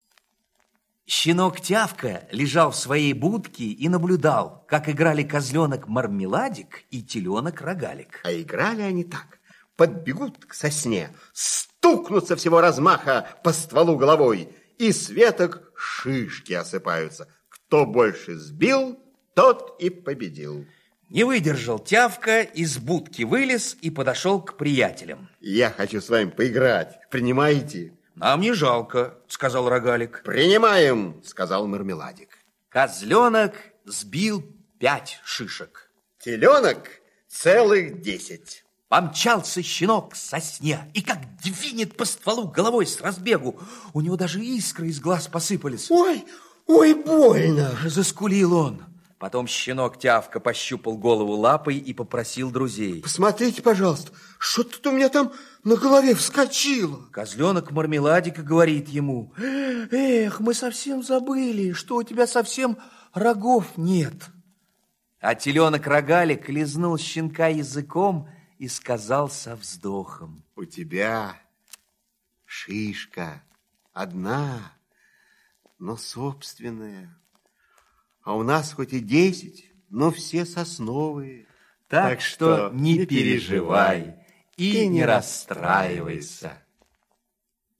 Слушайте Шишки - аудиосказка Пляцковского М.С. Сказка про щенка, который решил поиграть с козленком и теленком, сбивавшими шишки с сосны.